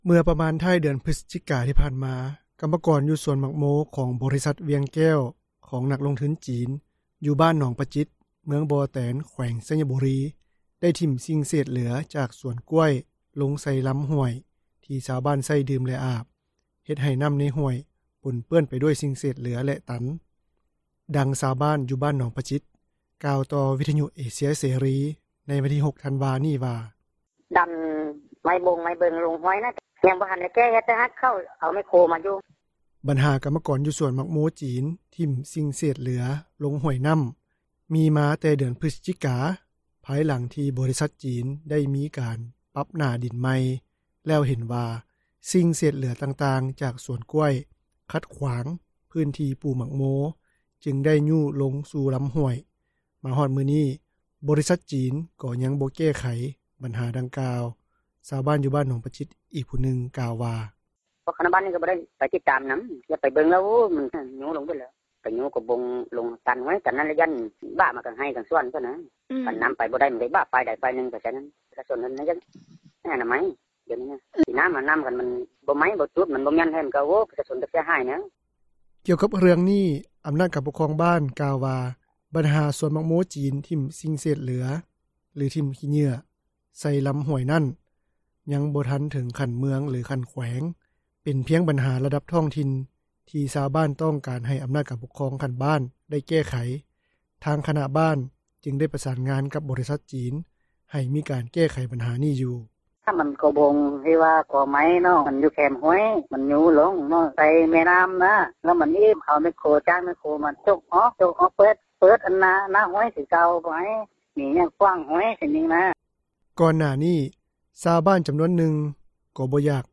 ເມື່ອປະມານ ທ້າຍເດືອນພຶສຈິກາ ທີ່ຜ່ານມາ ກັມມະກອນ ຢູ່ສວນໝາກໂມ ບໍຣິສັດວຽງແກ້ວ ຂອງນັກລົງທຶນຈີນ ຢູ່ບ້ານໜອງປະຈິດ, ເມືອງບໍ່ແຕນ ແຂວງໄຊຍະບູຣີ ໄດ້ຖິ້ມສິ່ງເສດເຫຼືອ ຈາກສວນກ້ວຍ ລົງໃສ່ລຳຫ້ວຍ ທີ່ຊາວບ້ານໃຊ້ດື່ມ ແລະ ອາບ ເຮັດໃຫ້ນ້ຳໃນຫ້ວຍ ປົນເປື້ອນໄປດ້ວຍສິ່ງເສດເຫຼືອ ແລະ ຕັນ. ດັ່ງຊາວບ້ານ ຢູ່ບ້ານໜອງປະຈິດ ກ່າວຕໍ່ວິທຍຸເອເຊັຽເສຣີ ໃນວັນທີ 6 ທັນວາ ນີ້ວ່າ:
ກ່ອນໜ້ານີ້ ຊາວບ້ານ ຈຳນວນນຶ່ງ ກໍບໍ່ຢາກຍອມເອົາດິນ ໃຫ້ຄົນຈີນເຊົ່າ ເນື່ອງຈາກພວກເຂົາເຈົ້າ ກັງວົນເຖິງຜົລກະທົບ ດ້ານສິ່ງແວດລ້ອມ ທີ່ຈະກະທົບກັບດິນປູກຝັງ ຂອງເຂົາເຈົ້າ, ແຕ່ກໍຕ້ອງຍອມເອົາດິນ ໃຫ້ຄົນຈີນເຊົ່າ ທົດລອງປູກໝາກໂມ ເນື່ອງຈາກພາຍໃນບ້ານ ໄດ້ຮ່ວມກັນລົງປະຊາມະຕິ ແລ້ວເອົາສຽງສ່ວນຫຼາຍ ທີ່ສນັບສນູນໃຫ້ຈີນ ເຊົ່າດິນປູກໝາກໂມ ເປັນມະຕິເອກະພາບກັນ. ຊາວບ້ານ ຢູ່ບ້ານໜອງປະຈິດ ອີກຜູ້ນຶ່ງ ກ່າວວ່າ: